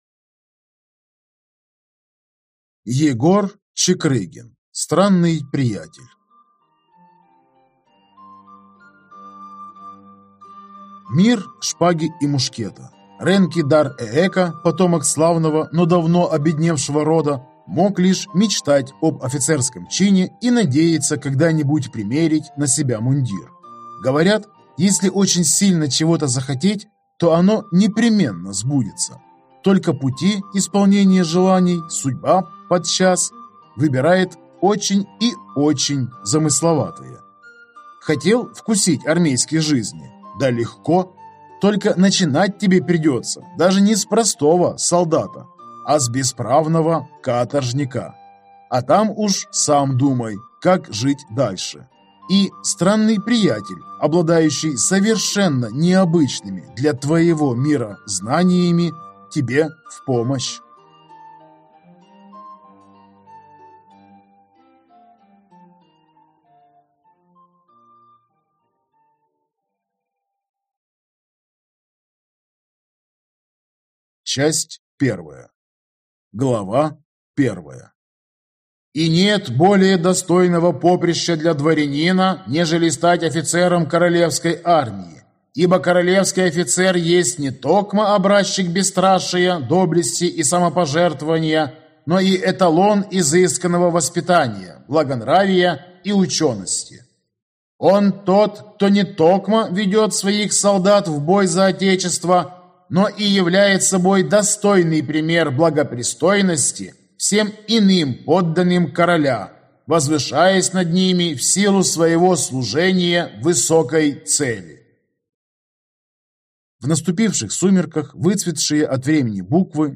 Аудиокнига Странный приятель | Библиотека аудиокниг